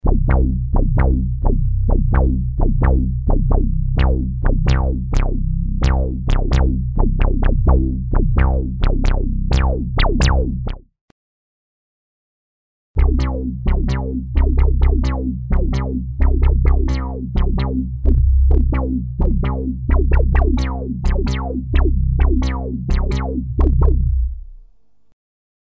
Bass sounds, poly mod I then unison, some tweaking with filter env and resonance.
Unease_Juno-106_bass.mp3